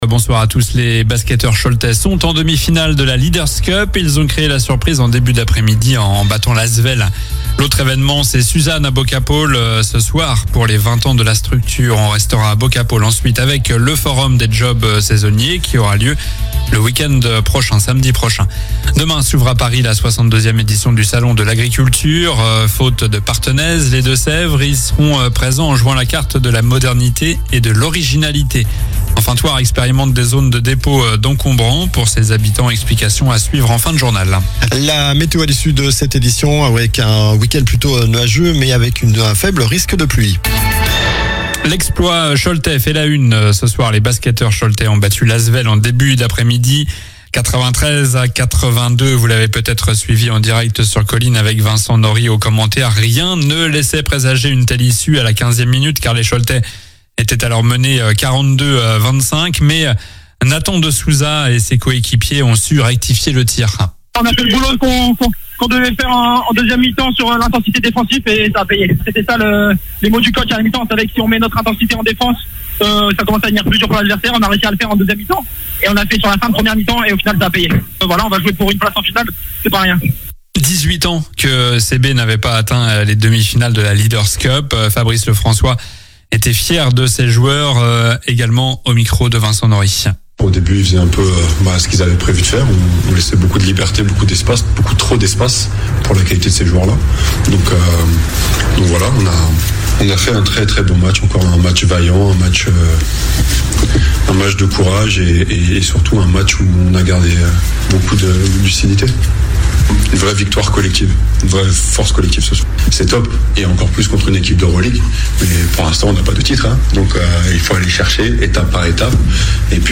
Journal du vendredi 20 février (soir)